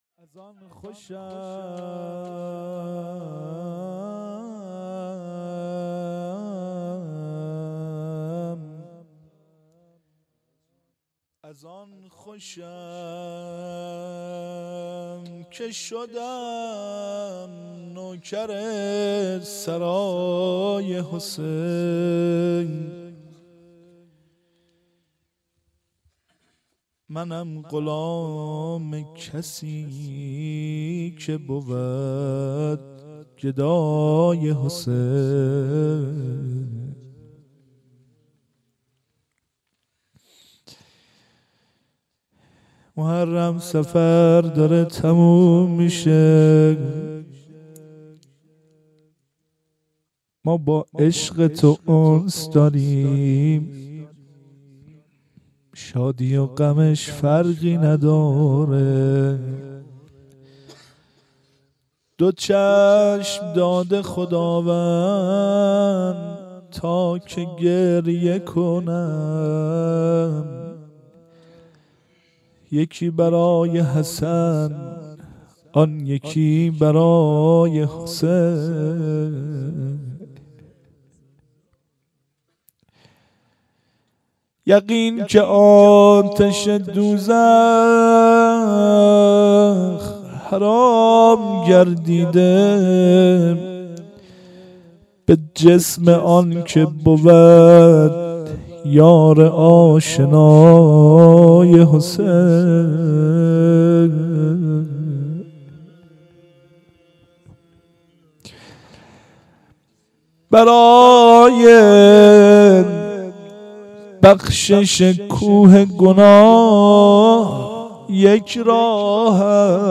شب اول روضه شهادت حضرت رسول (ص) 1394
روضه آخر